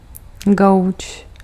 Ääntäminen
IPA : /ˈsoʊ.fə/ US : IPA : [ˈsoʊ.fə]